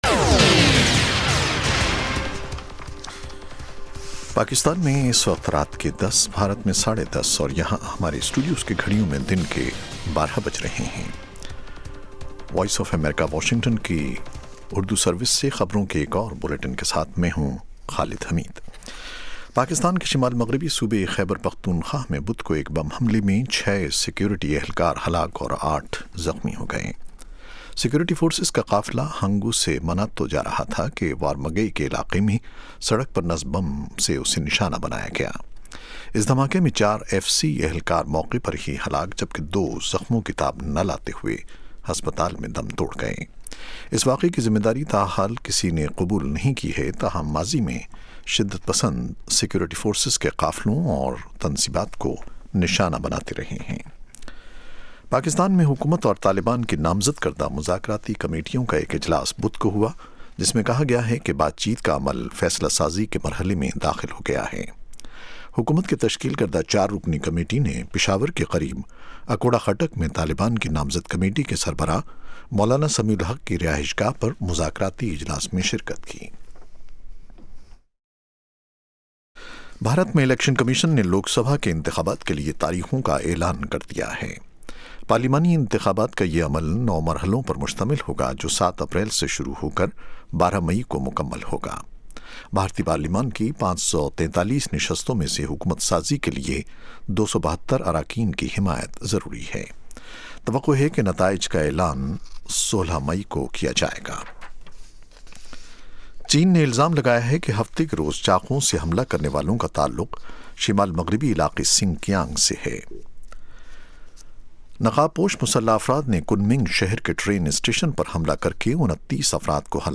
اس پروگرام میں تجزیہ کار اور ماہرین سیاسی، معاشی، سماجی، ثقافتی، ادبی اور دوسرے موضوعات پر تفصیل سے روشنی ڈالتے ہیں۔